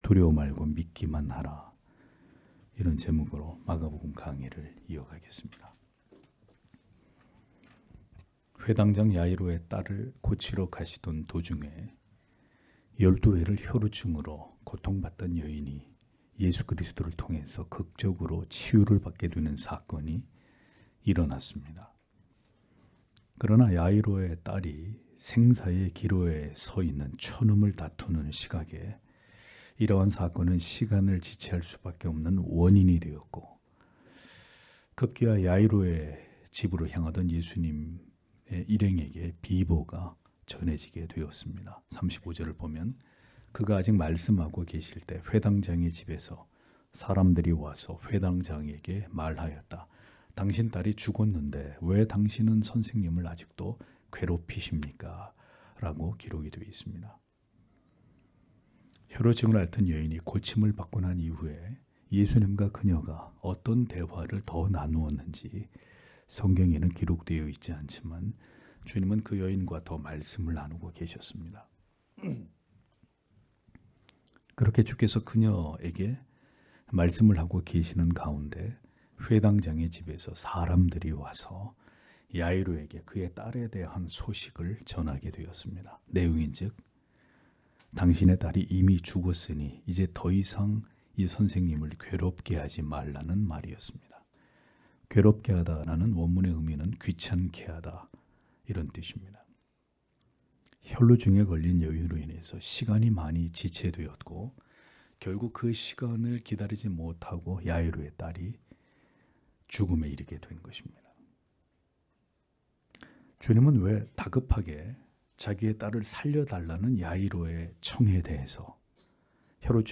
주일오전예배 - [마가복음 강해 19] 두려워 말고 믿기만 하라 (막 5장 35-43절)